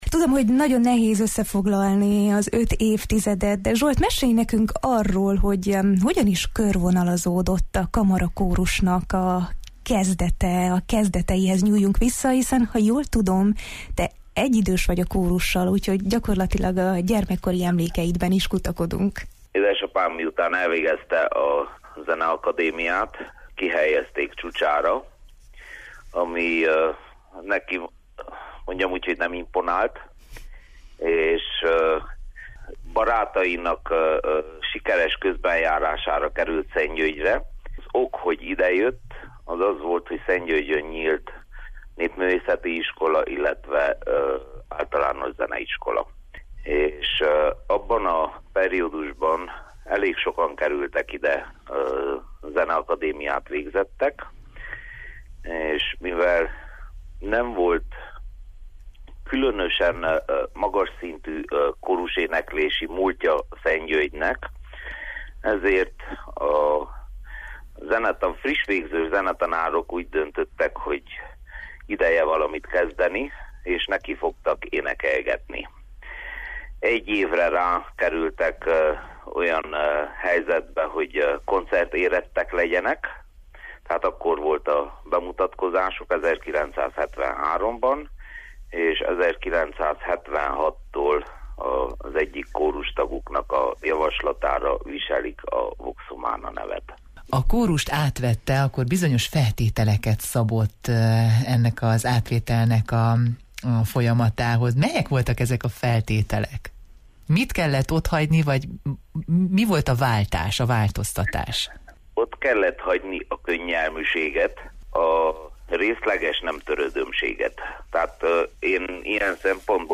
Jó reggelt, Erdély!-ben beszélgettünk.